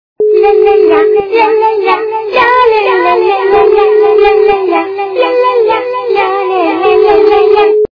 Девочка - Поет песенку (ля-ля-ля) Звук Звуки Дівчинка - співає пісеньку (ля-ля-ля)
» Звуки » Смешные » Девочка - Поет песенку (ля-ля-ля)
При прослушивании Девочка - Поет песенку (ля-ля-ля) качество понижено и присутствуют гудки.